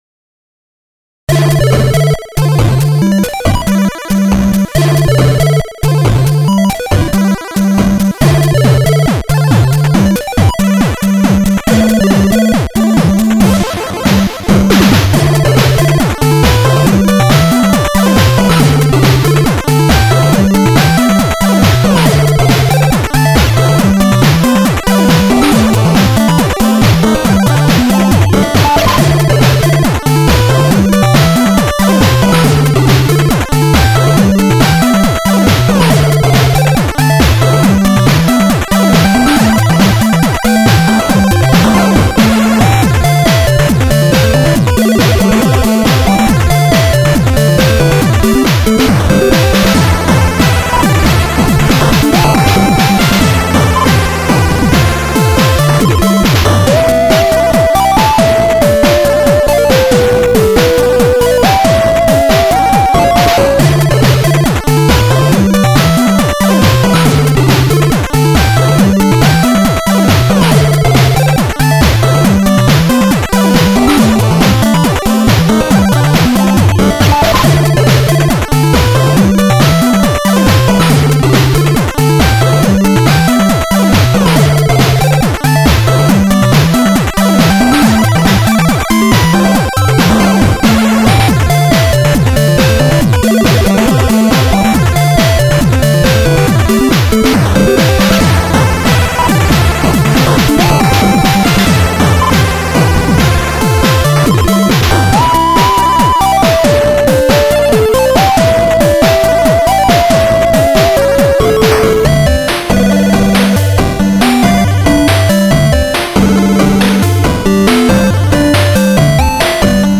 ※ (　　)内は、使用音源チップです。
(2A03)